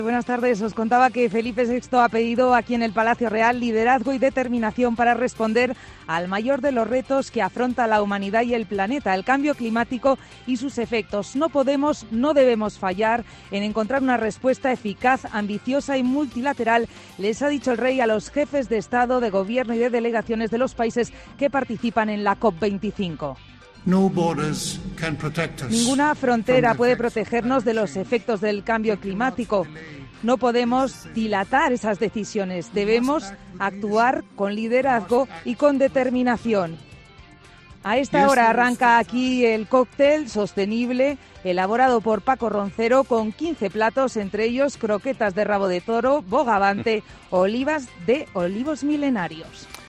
Intervención del Rey Felipe VI en la Cumbre del Clima